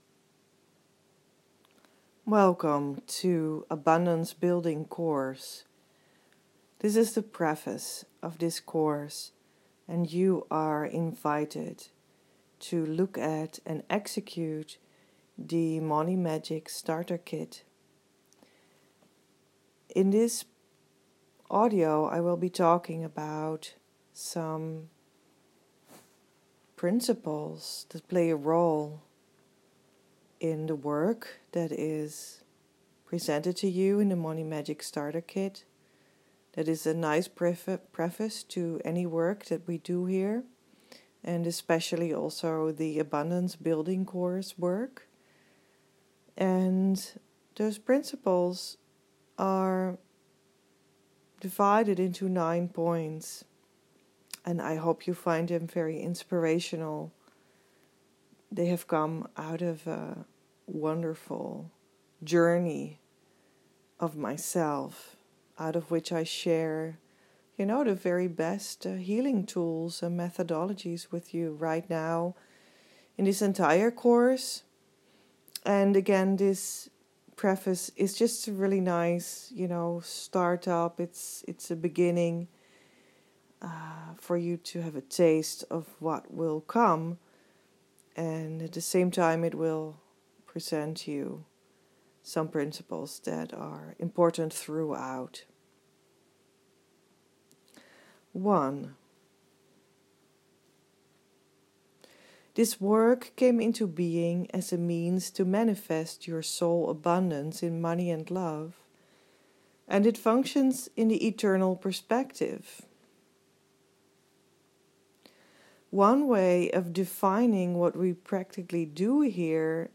Audio Lesson – 56 minutes, you can listen to this like it is a podcast